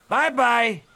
Tags: ASA Sports announcing